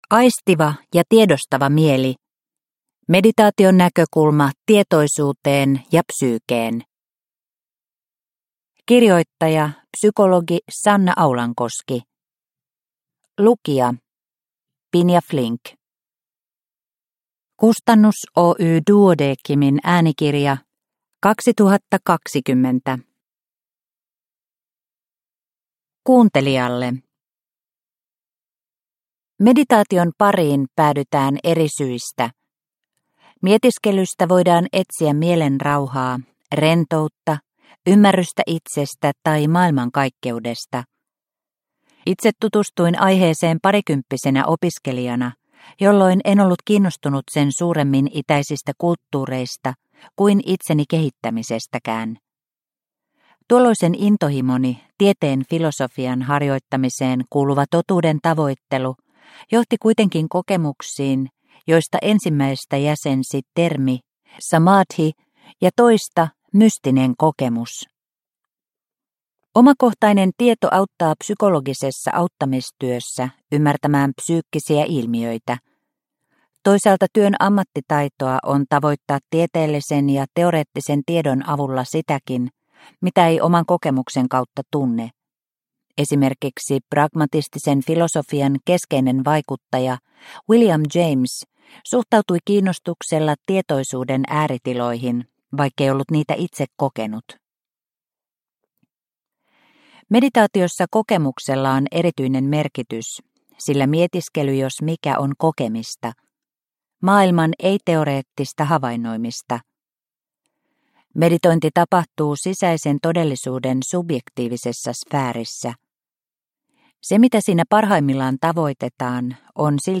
Aistiva ja tiedostava mieli – Ljudbok – Laddas ner